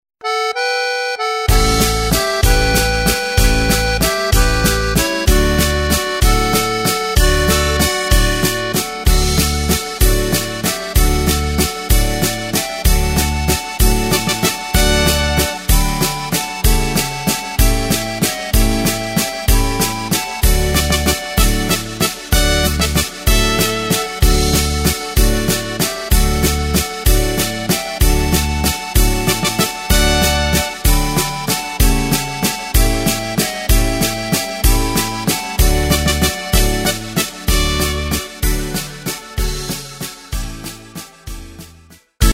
Takt: 3/4 Tempo: 190.00 Tonart: D
Volkslied aus dem Jahr 1958! OHNE MARSCH-INTRO!